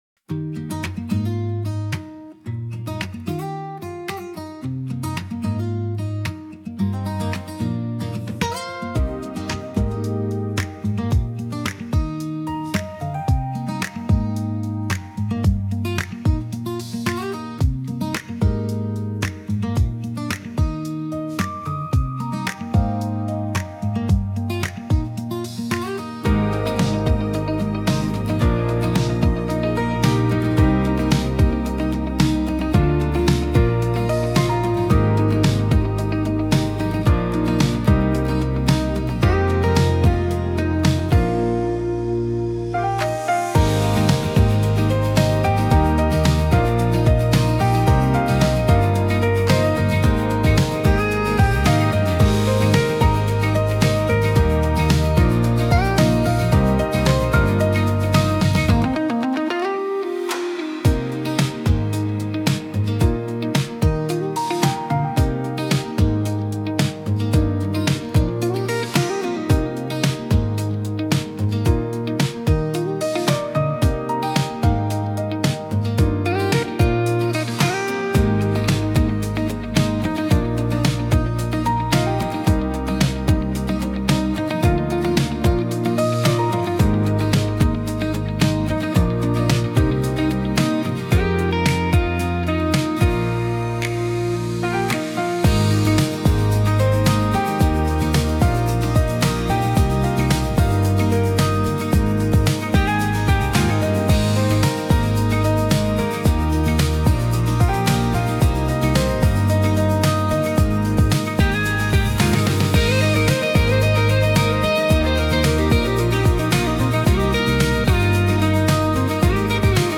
ボーカル無し
ポップス アコースティック 明るい